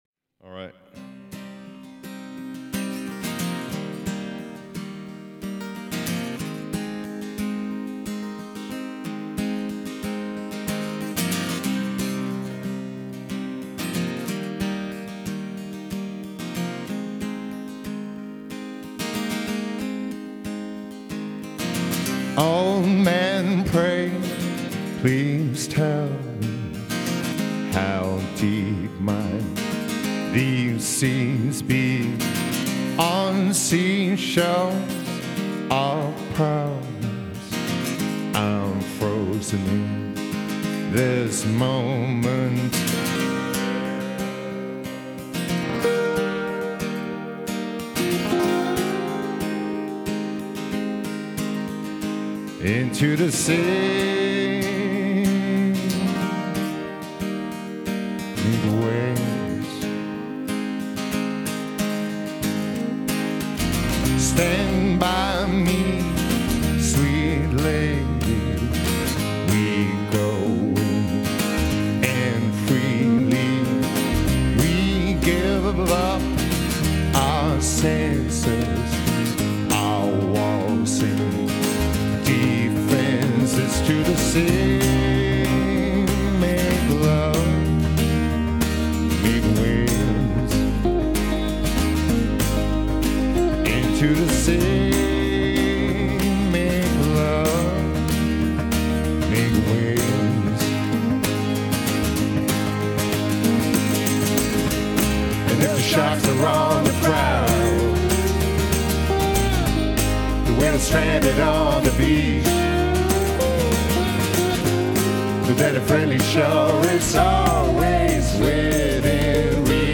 Norwegian singer-songwriter